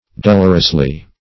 -- Dol"or*ous*ly, adv. -- Dol"or*ous*ness, n.